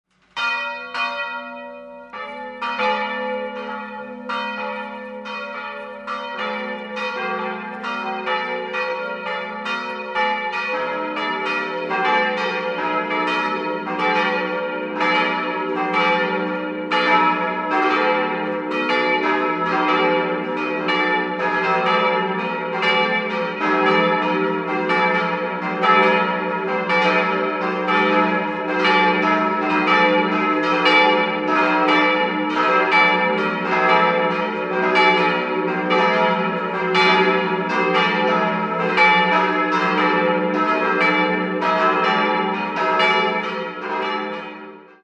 Die große Glocke stammt noch aus dem alten Geläut und wurde 1874 von Eduard Becker in Ingolstadt gegossen. Die beiden mittleren goss 1950 Karl Czudnochowsky in Erding und die kleine ist ein Werk von Karl Hamm (Regensburg) und entstand 1923.